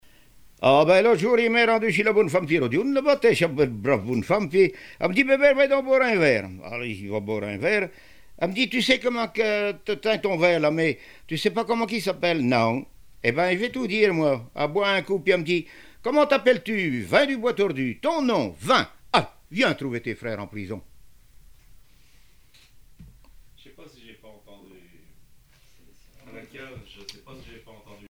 Saint-Hilaire-de-Riez
circonstance : bachique
Genre formulette